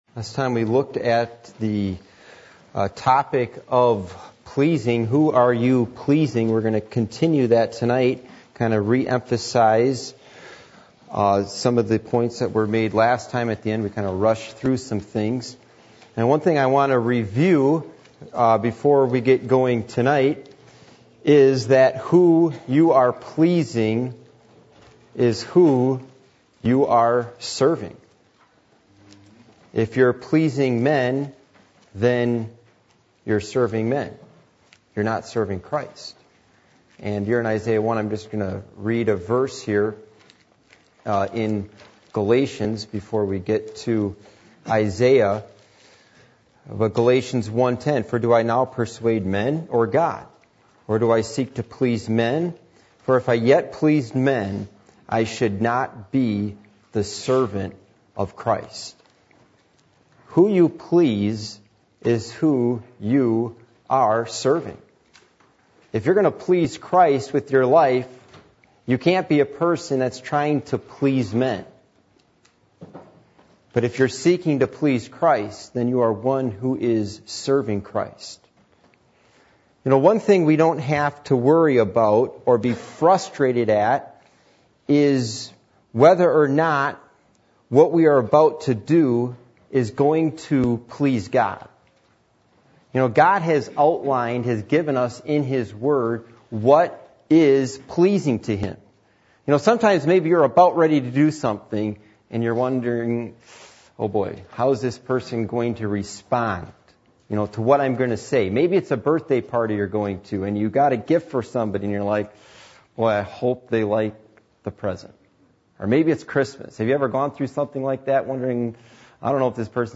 Passage: Isaiah 1:11-17, Psalm 51:16-17 Service Type: Midweek Meeting